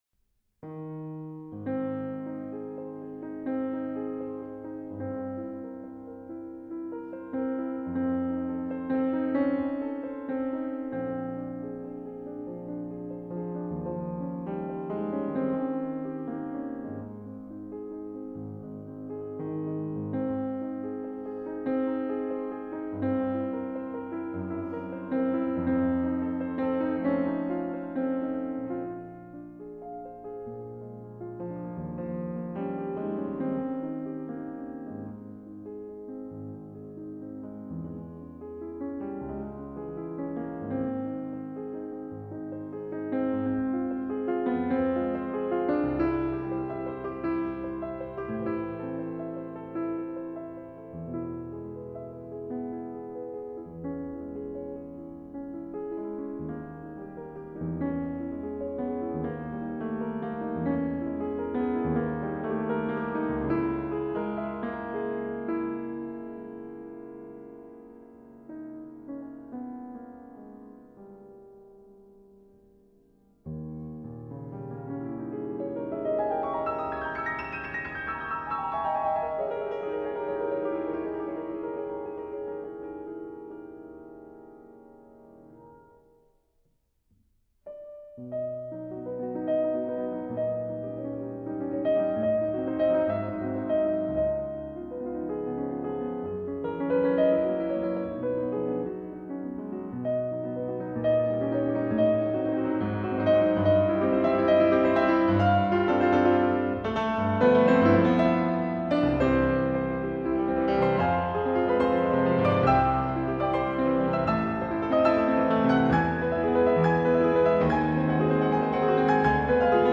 中国钢琴家